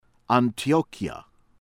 ACES AH-says